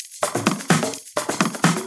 Index of /VEE/VEE Electro Loops 128 BPM
VEE Electro Loop 337.wav